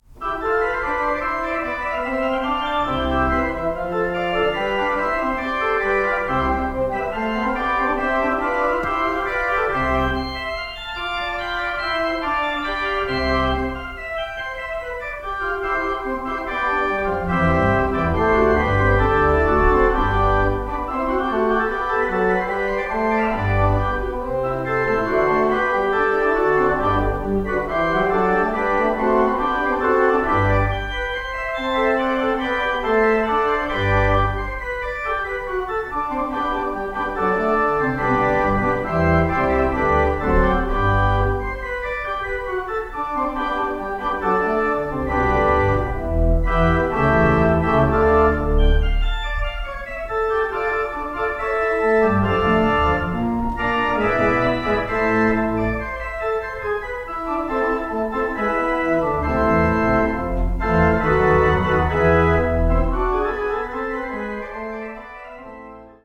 Calvary United Methodist Church, Keyport
1952 Wicks Pipe Organ